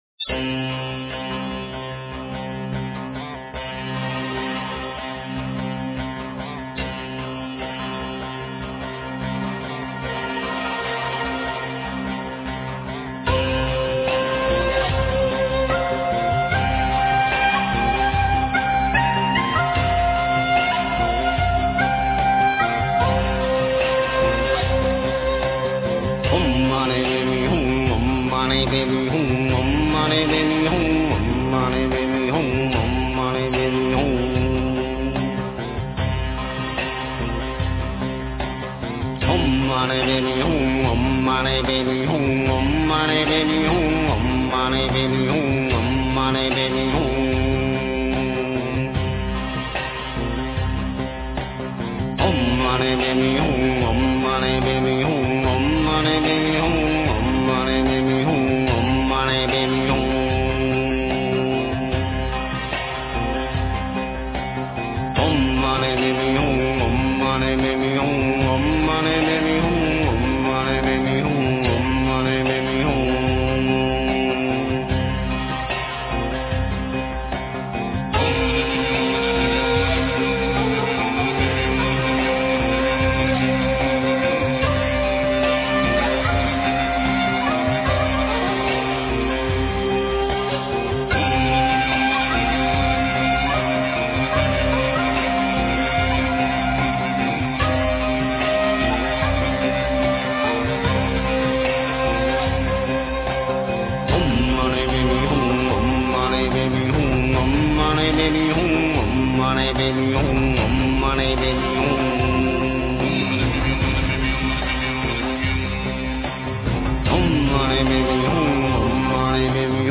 标签: 佛音真言佛教音乐